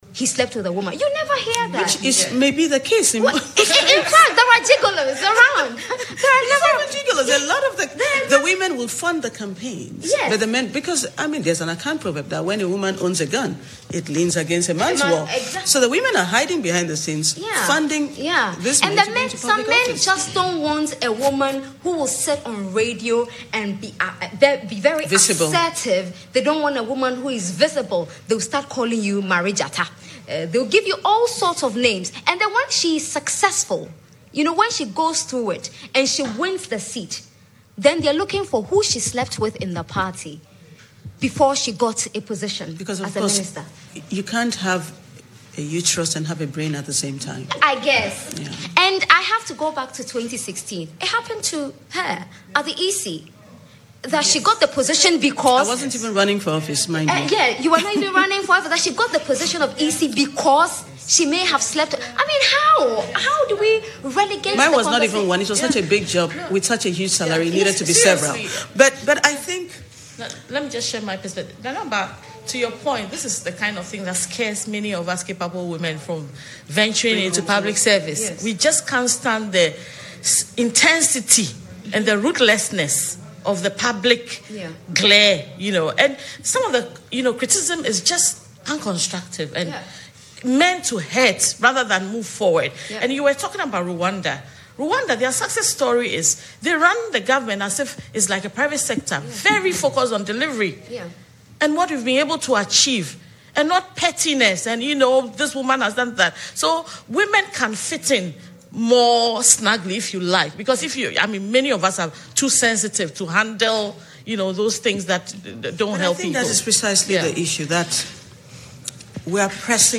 Charlotte Osei was speaking as host of Joy FM’s flagship programme, the Super Morning Show, to mark this year’s IWD.
Broadcast Journalist, Nana Aba Anamoah, who was a panelist on the show said, women in Ghana are born with talent but are held back by prejudice.